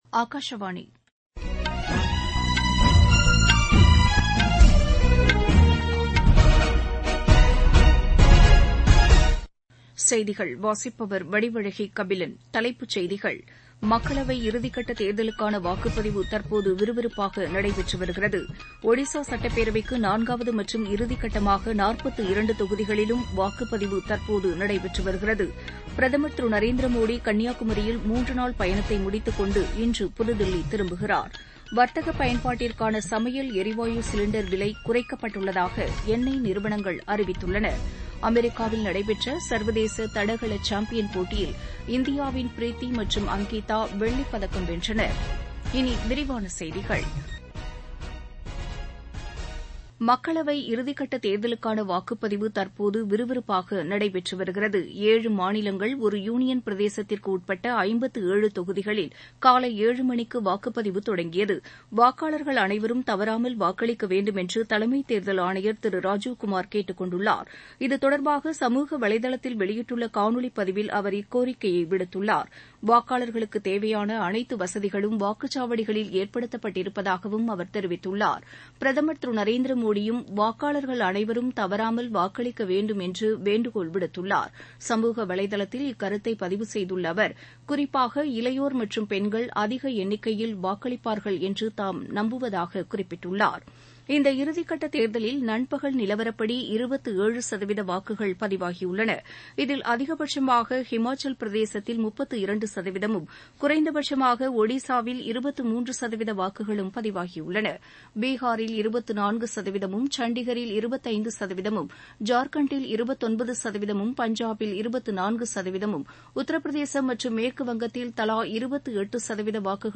Transcript summary Play Audio Morning News